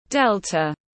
Delta /ˈdel.tə/